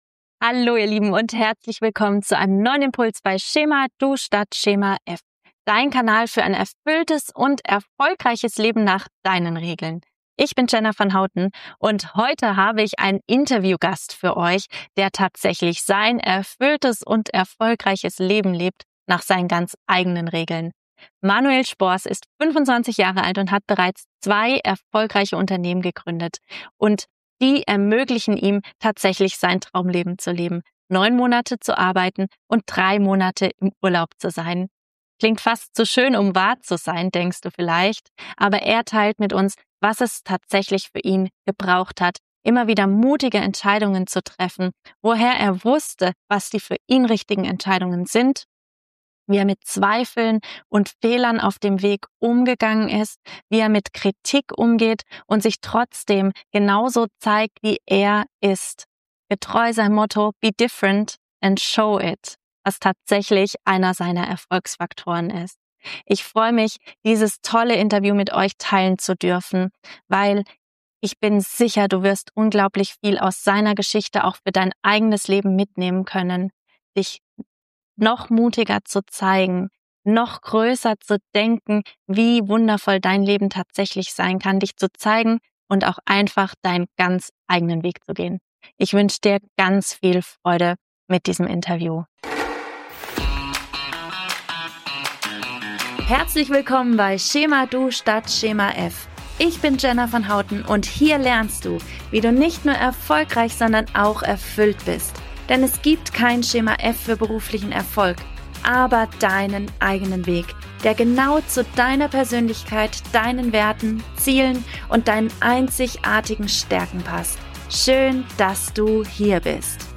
In dieser Folge veröffentliche ich ein besonderes Archiv-Interview, das ursprünglich auf YouTube erschienen ist.